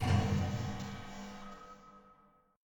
LightSwitch.ogg